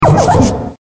Sounds / Cries